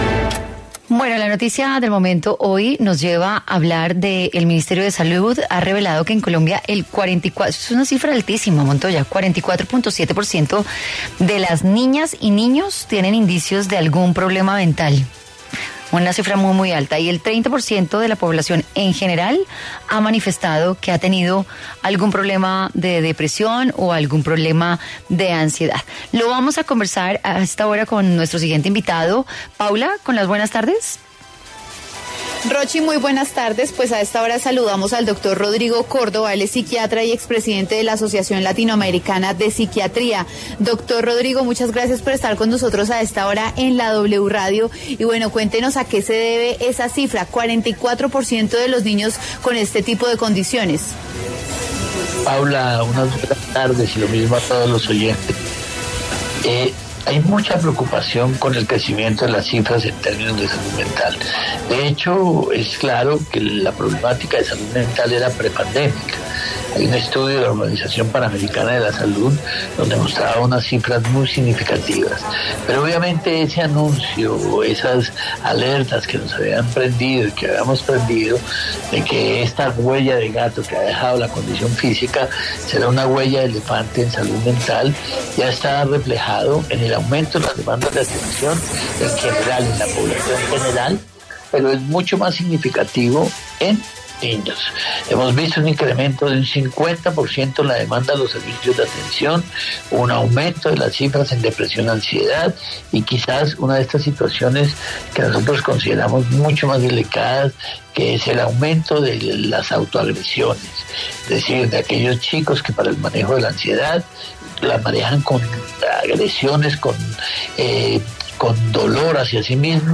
Tema del DíaEspeciales